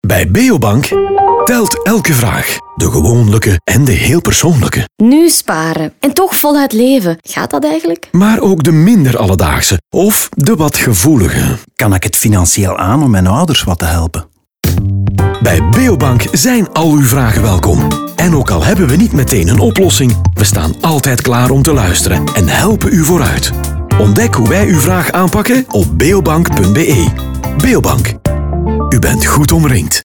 Sound studio: Cobra
Beobank B1a Radio Insert NL.mp3